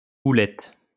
Houlette (French pronunciation: [ulɛt]